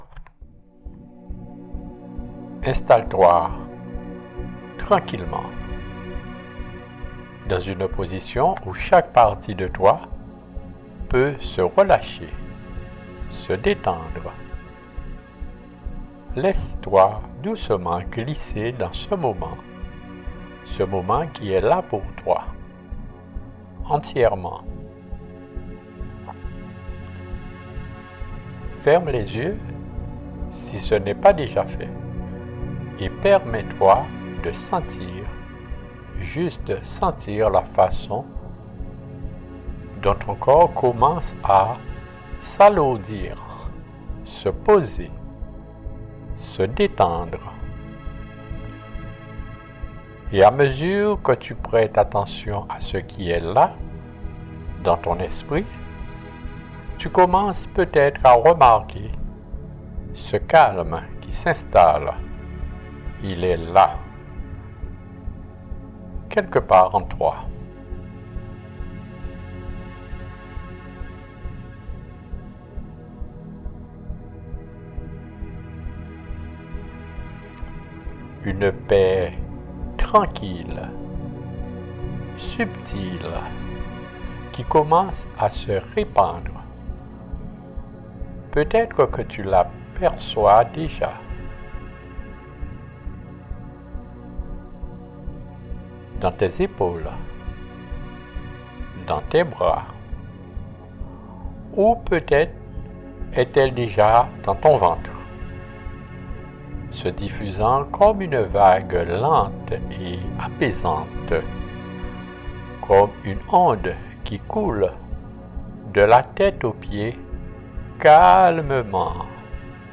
La musique d'accompagnement MusicFX AI de Google créera une ambiance propice à l'apprentissage.